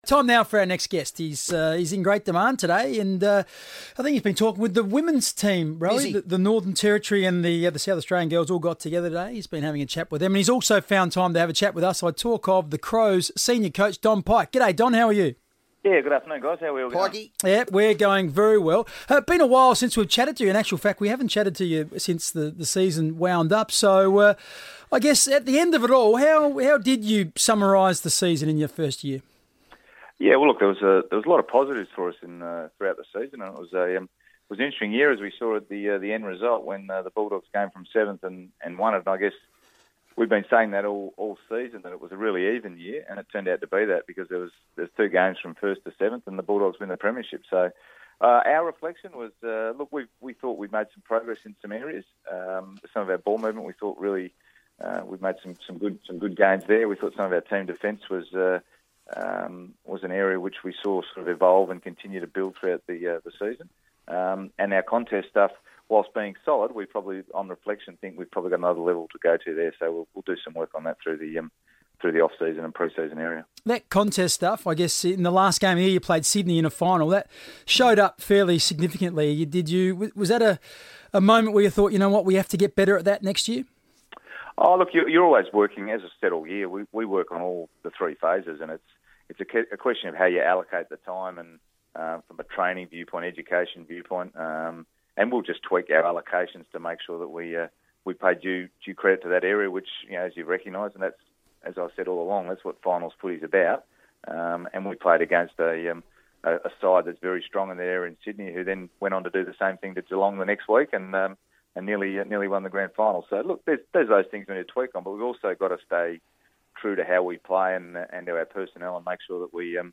Senior Coach Don Pyke spoke to the FIVEaa Sports Show ahead of the full squad's return to pre-season training.